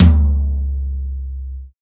TOM SHARPT07.wav